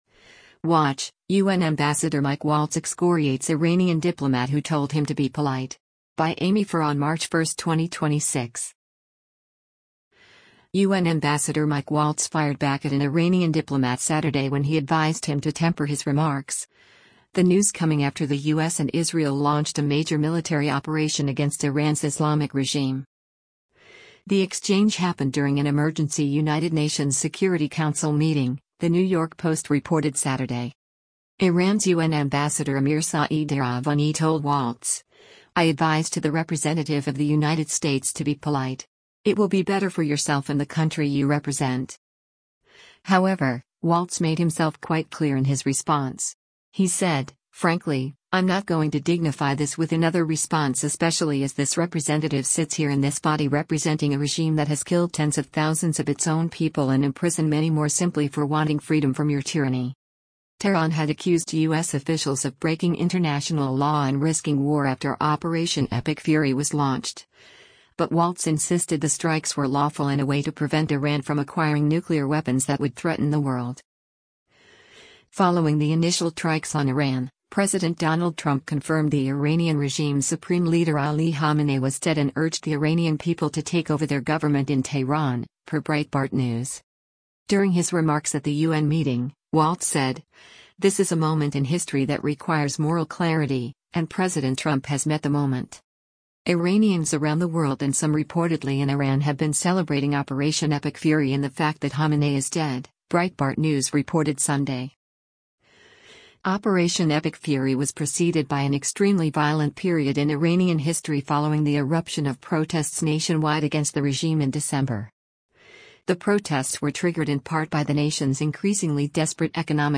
US National Security Advisor Mike Waltz addresses the Iranian delegation during a United N
The exchange happened during an emergency United Nations Security Council meeting, the New York Post reported Saturday.